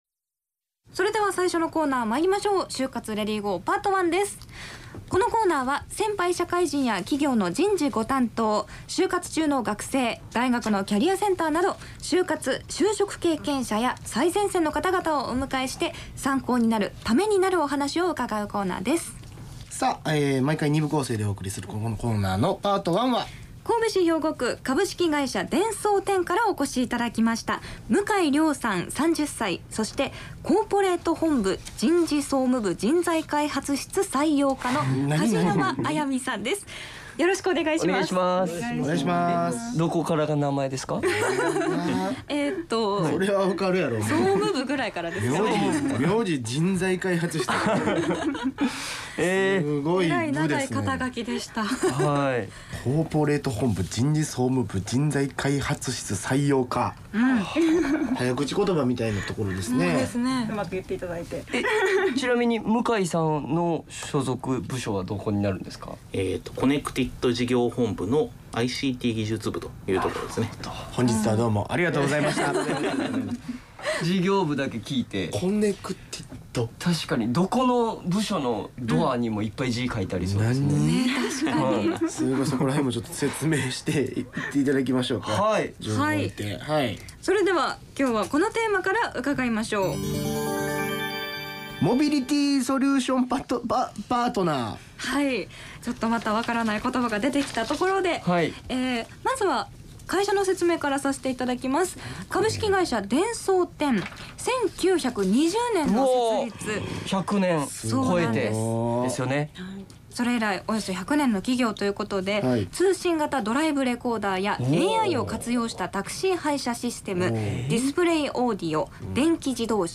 社会人として活躍する先輩たちは、いったいどんな就職活動を経験し、今日に至るのか。先輩社会人ロールモデルが、ラジオ番組でその実体験を語った。